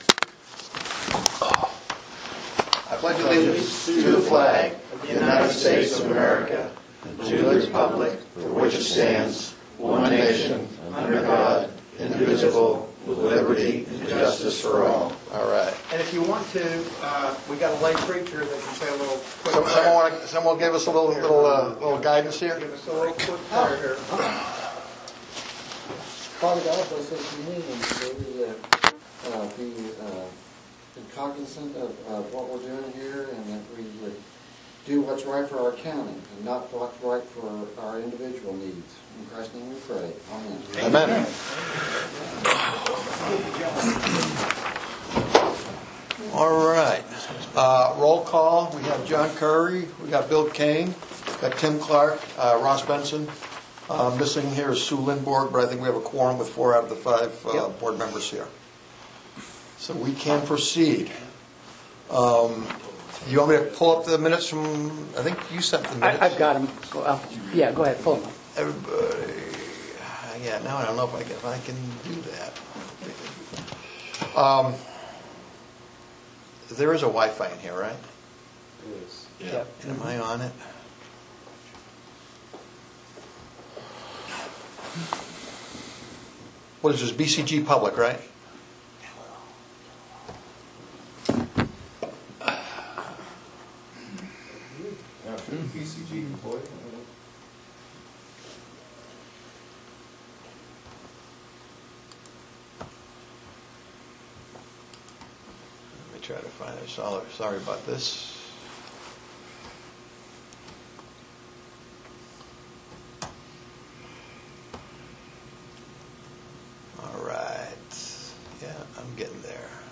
AUDIO of the Meeting RDC members present.